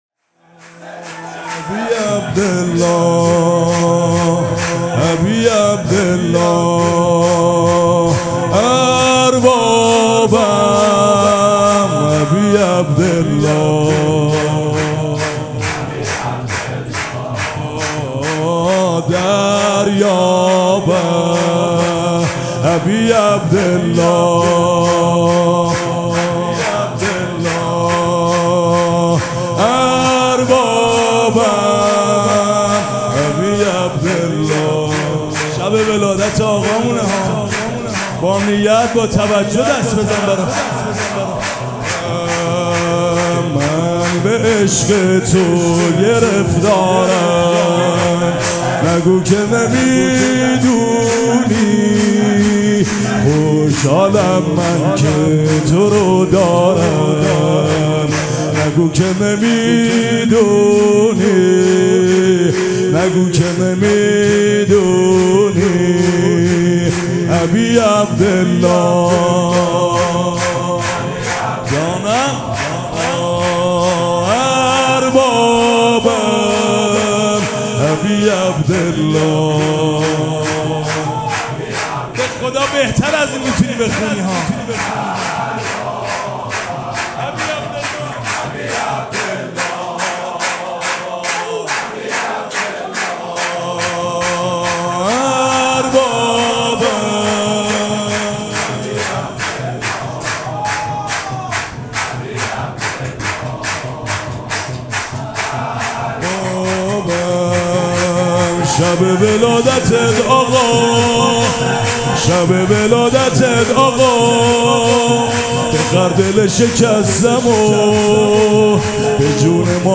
مراسم شب اول ولادت سرداران کربلا
شور
مداح